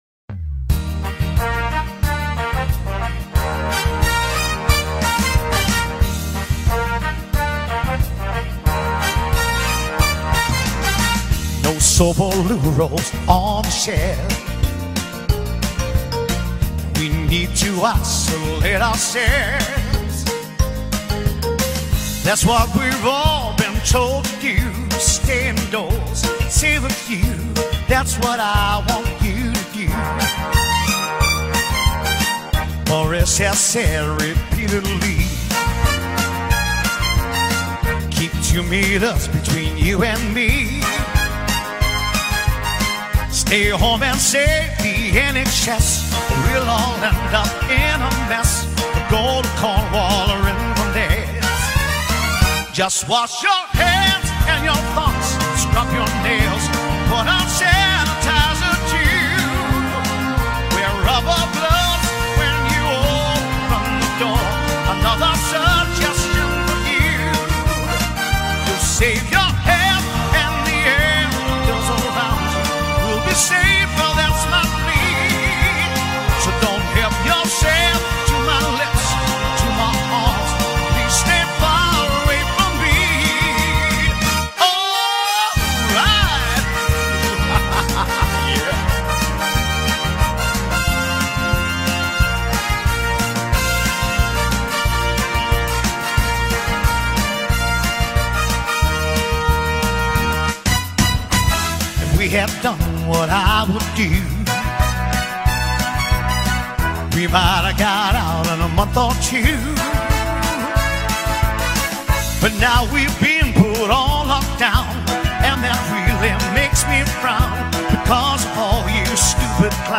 13) Yet Another Coronavirus Song Parody